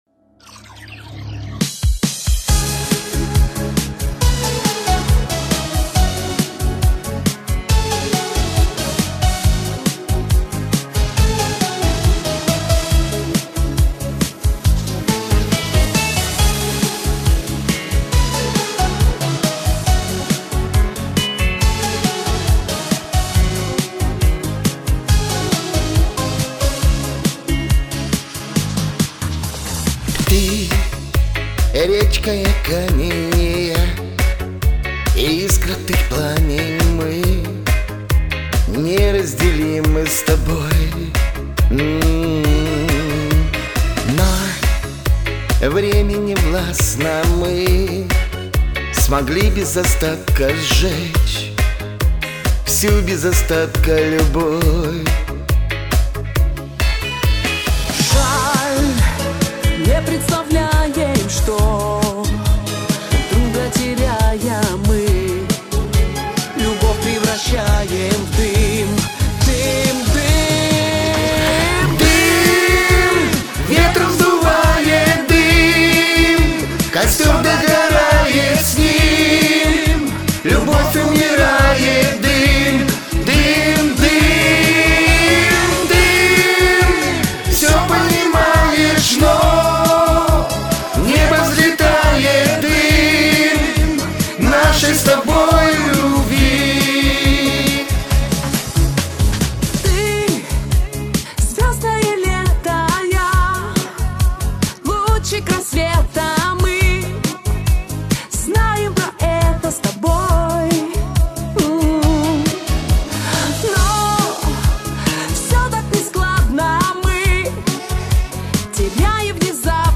ПЕСНЯ СПЕТА В ДУЭТЕ